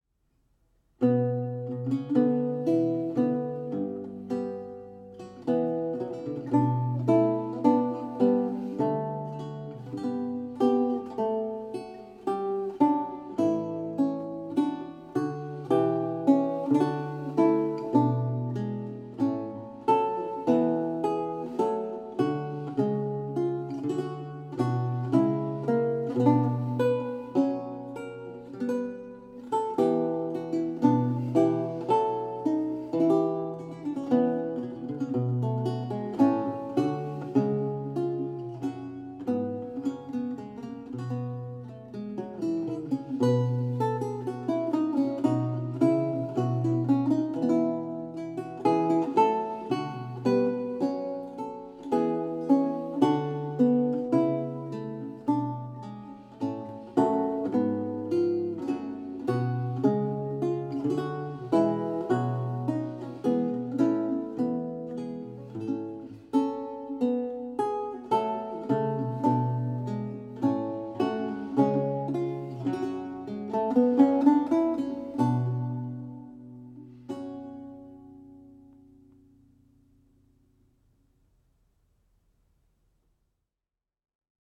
Audio recording of a lute piece from the E-LAUTE project